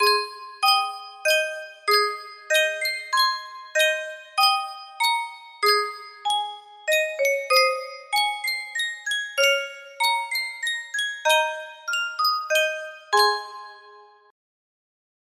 Sankyo Music Box - Bach Minuet No. 1 iCU music box melody
Full range 60